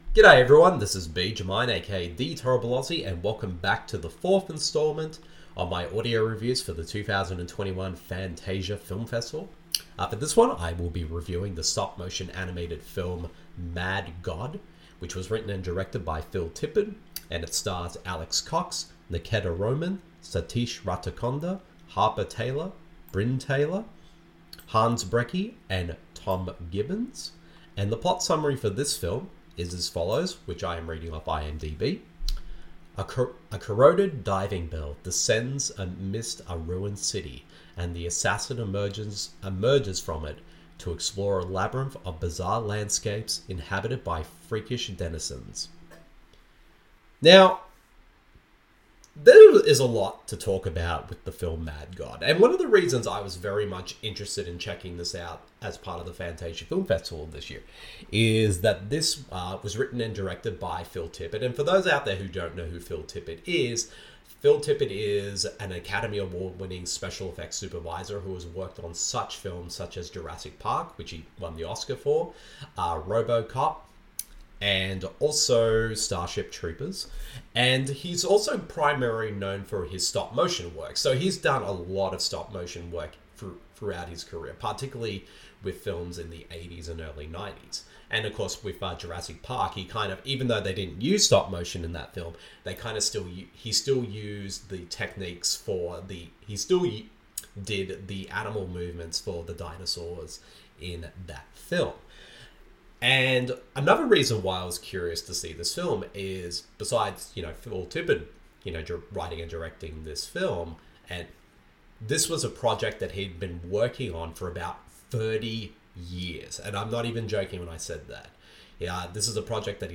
The following review of the film is in an audio format.